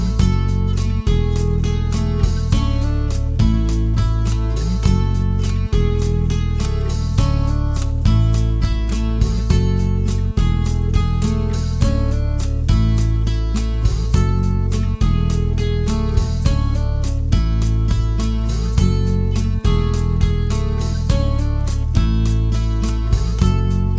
Two Semitones Up Pop (1980s) 4:55 Buy £1.50